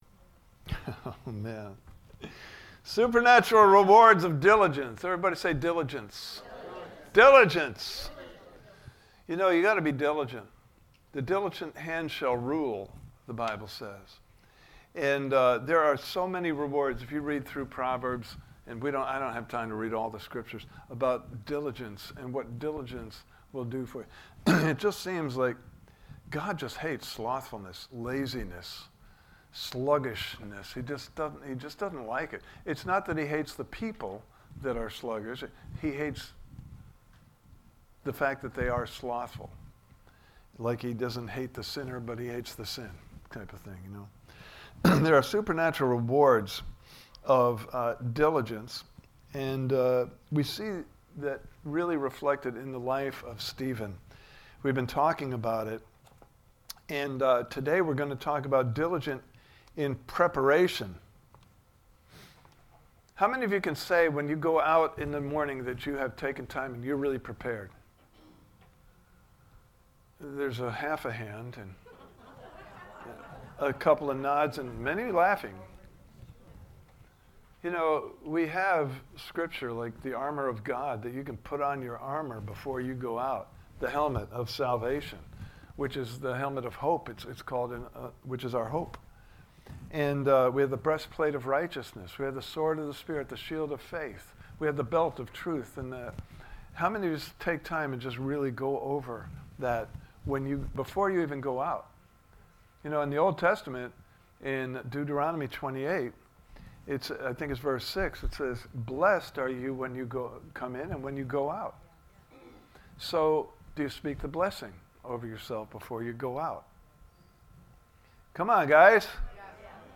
Series: The Supernatural Rewards Of Diligence Service Type: Sunday Morning Service